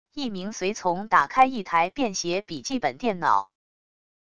一名随从打开一台便携笔记本电脑wav音频